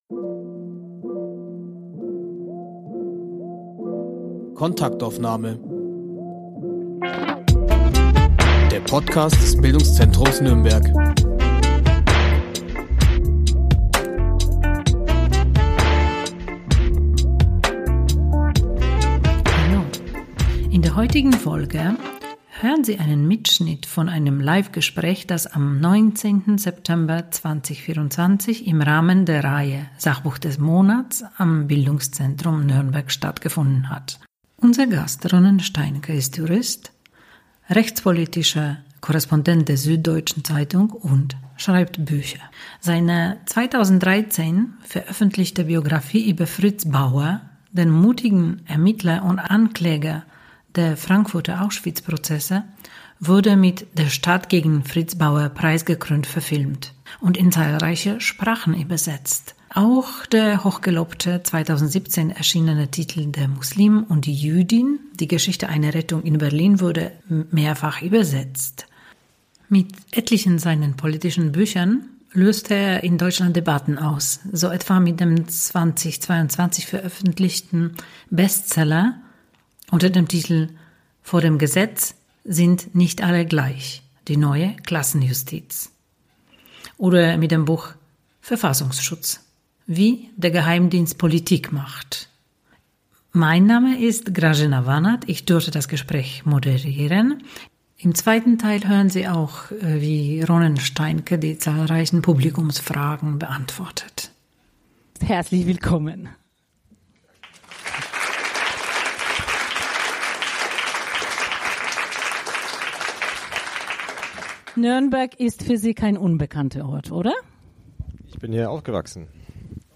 Mitschnitt einer Liveveranstaltung mit dem Juristen, Journalisten und Buchautor Ronen Steinke.